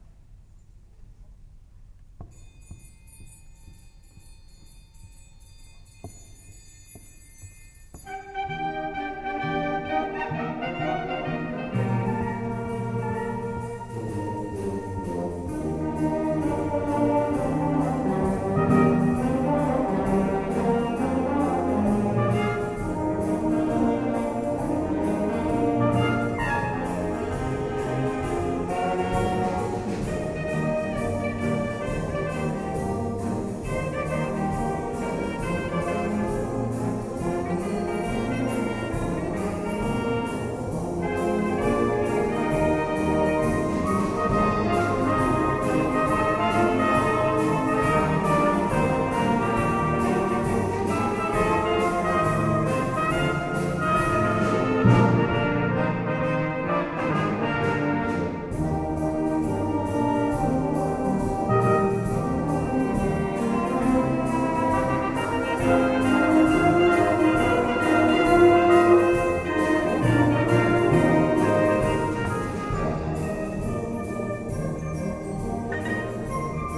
BANDA MUSICALE
Concerto di Natale 2011